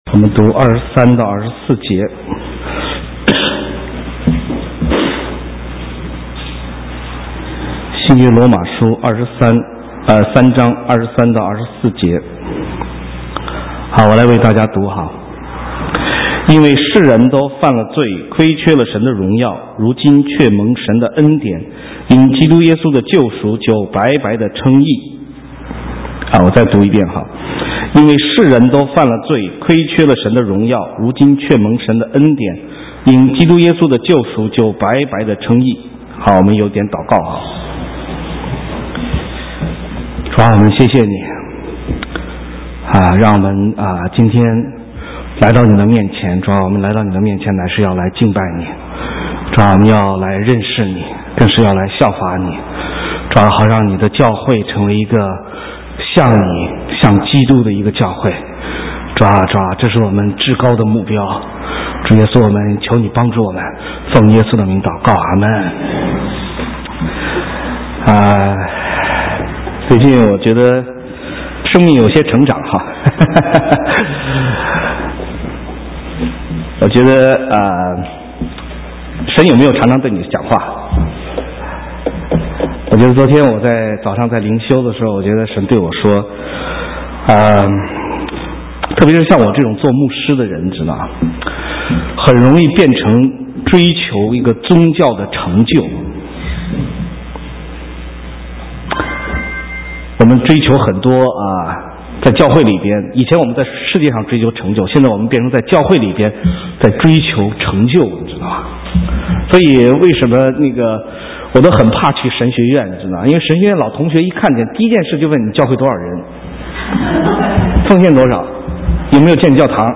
神州宣教--讲道录音 浏览：一个有耶稣样式的教会 (2012-01-08)